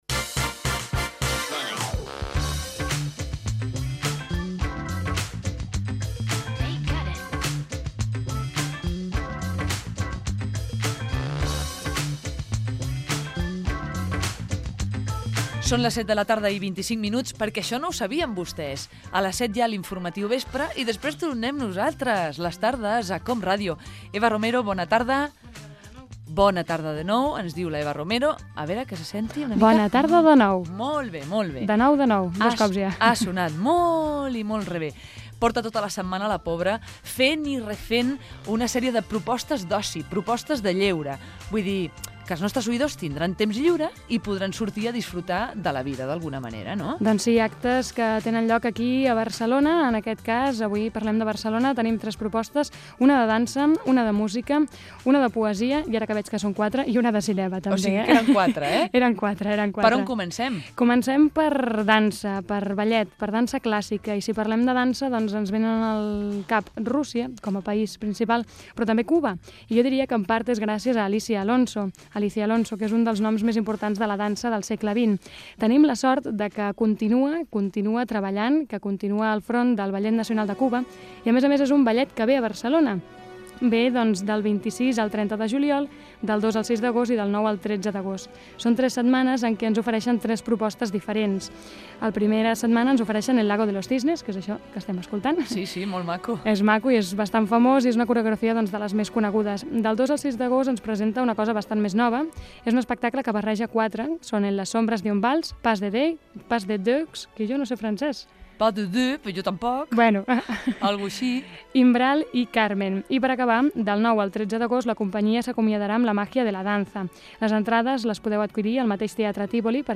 Fragment d'una entrevista a Alícia Alonso, directora del Ballet Nacional de Cuba.
Entreteniment
FM
Fragment extret de l'arxiu sonor de COM Ràdio.